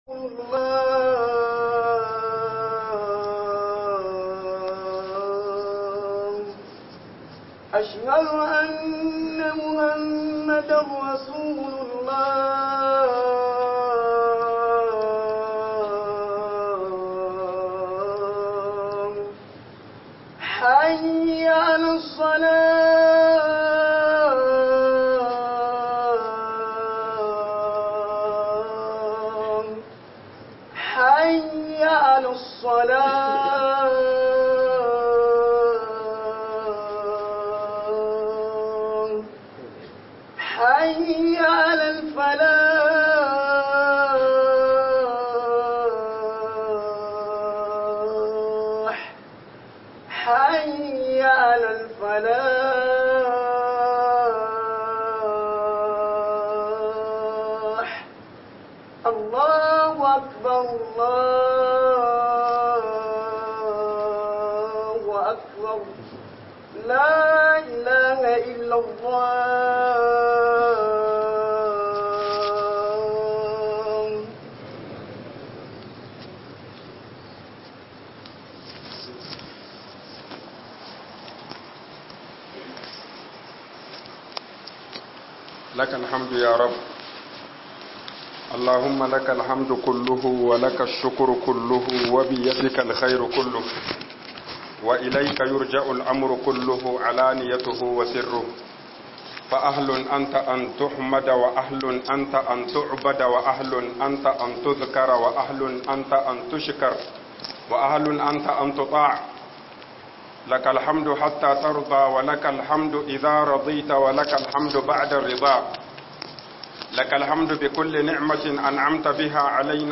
HUDUBA SIDDIQ - HUƊUBOBIN JUMA'A